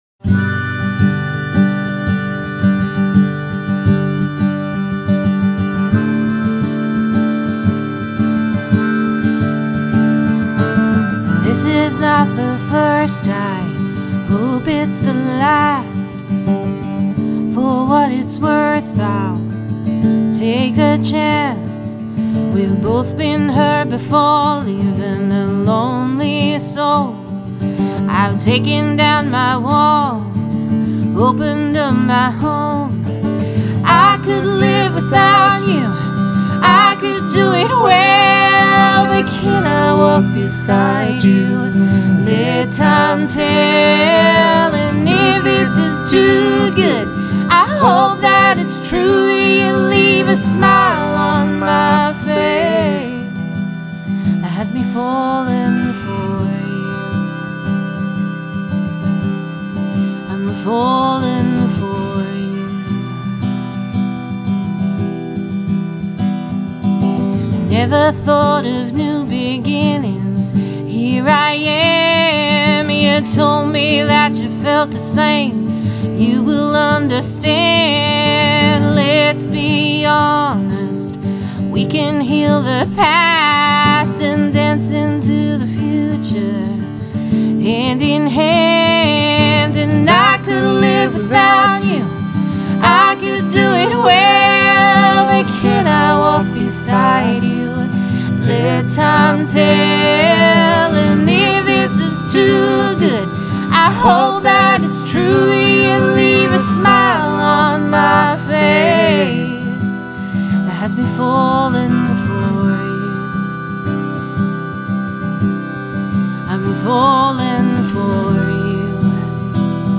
harmonica
harmony vocals